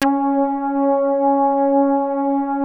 P.5 C#5.8.wav